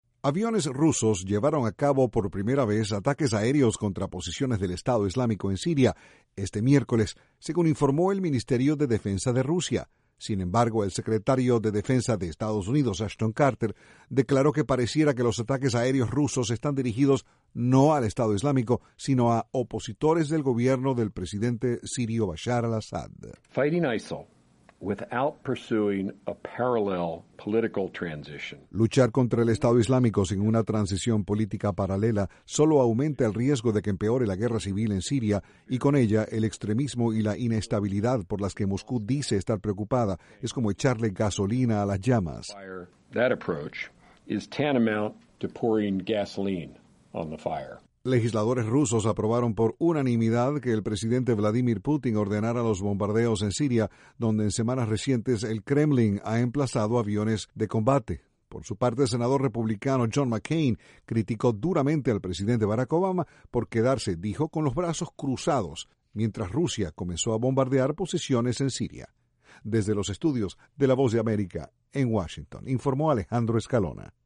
Para el Secretario de Defensa de Estados Unidos, los ataques aéreos rusos en Siria parecieran no estar dirigidos al Estado Islámico. Desde la Voz de América, Washington, informa